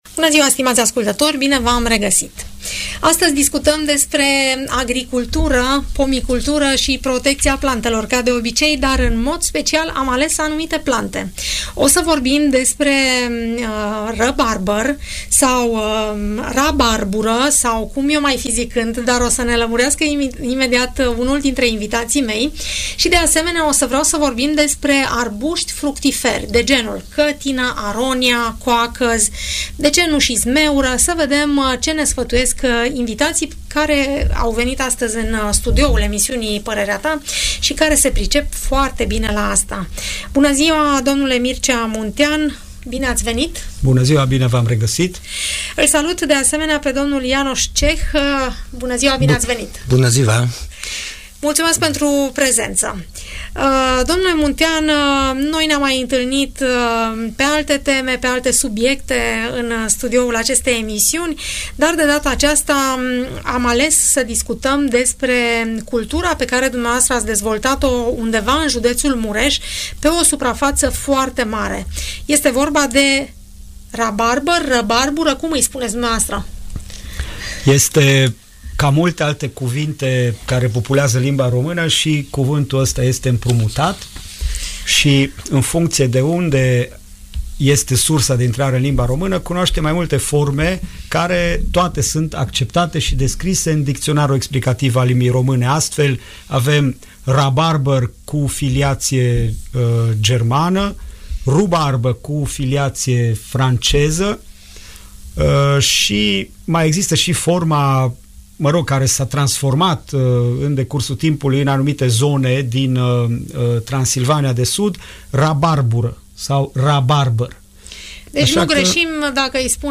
Răbarbura, cătina sau aronia, merită să fie cultivate în grădinile noastre. Despre plantarea, înmulțirea sau tratamentele acestora ne vorbesc la Radio Tg. Mureș, 2 fermieri care s-au specializat în cultivarea lor.